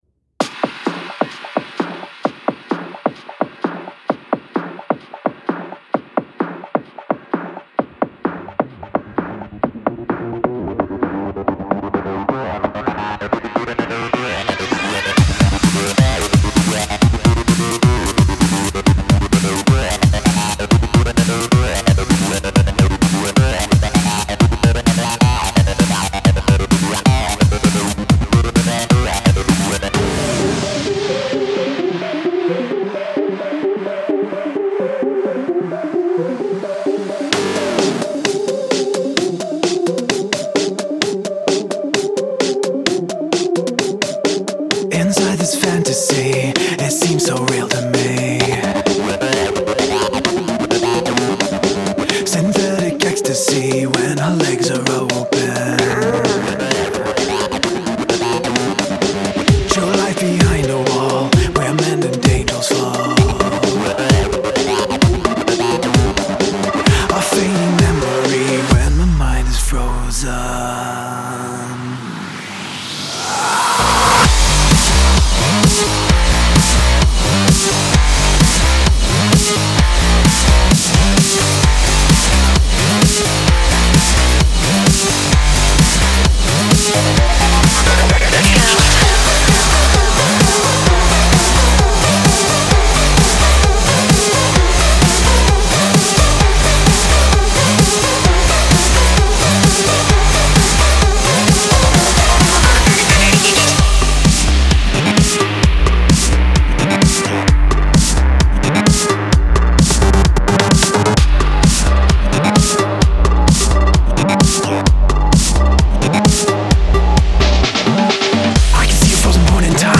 BPM130-130
Audio QualityPerfect (High Quality)
Industrial Rock song for StepMania, ITGmania, Project Outfox
Full Length Song (not arcade length cut)